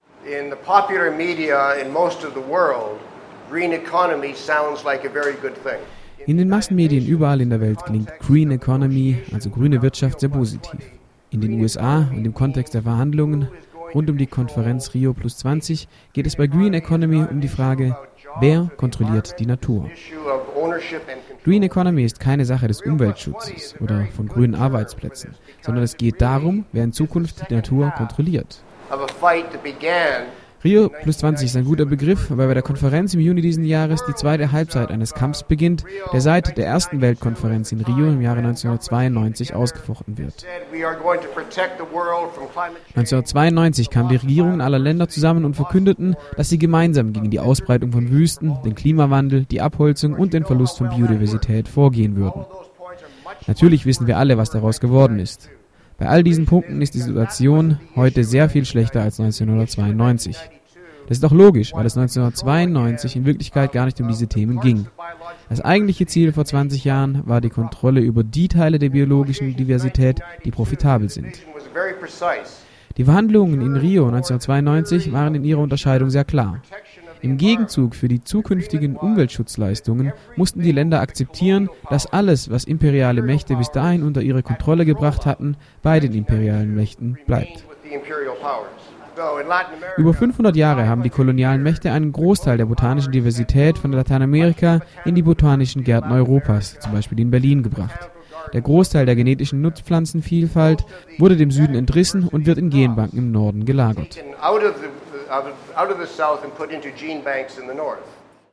in einem Vortrag beim SpzialForum in Rio de Janeiro